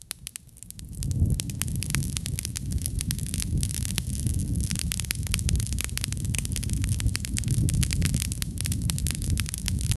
fire-burning-sounds--hxgxflwh.wav